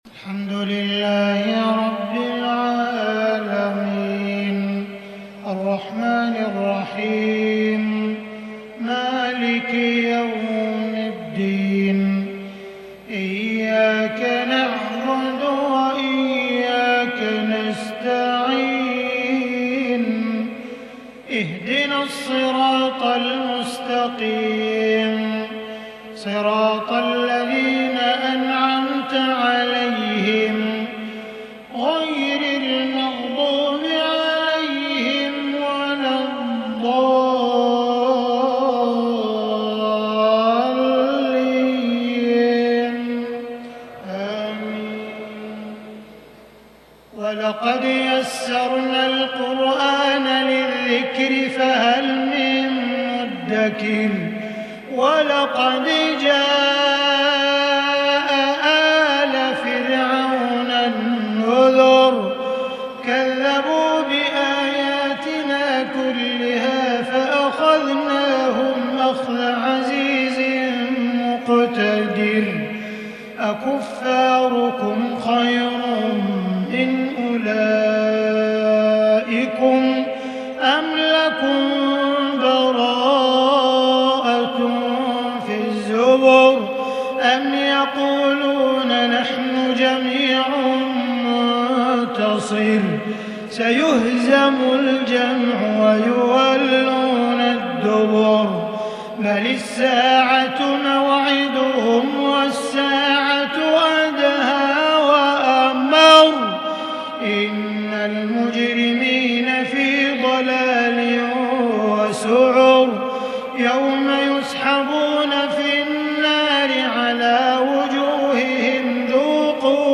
عشاء 8-4-1442هـ من سورتي القمر و الواقعة Isha prayer from Surah Al-Qamar and Al-Waaqia 23/11/2020 > 1442 🕋 > الفروض - تلاوات الحرمين